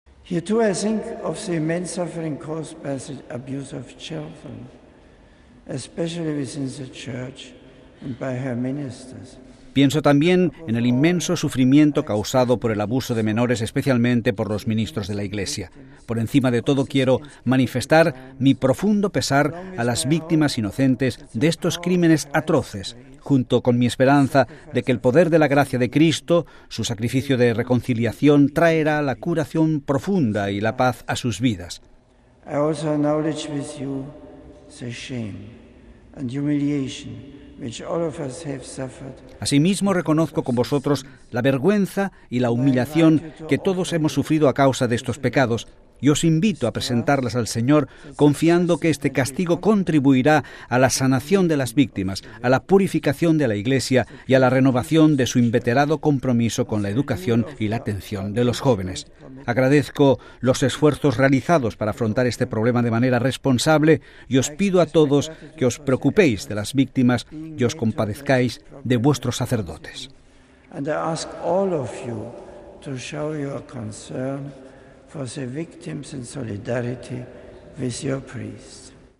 La Preciosísima Sangre de Cristo, a la que está dedicada la Catedral de Westminster y el gran crucifico que domina la nave central fueron el punto de partida de la homilía de Benedicto XVI en la Santa Misa de esta mañana, en Londres, en la que recordó a los que sufren discriminación y persecución por su fe cristiana y a los menores victimas de abusos por parte de los ministros de la iglesia.